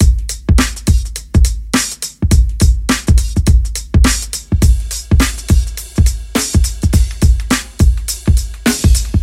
野生风铃
Tag: 竖琴 循环 电子 风铃 130bpm 环境 玻璃 吉他 效果 130bpm 电动 大气 气氛 ARP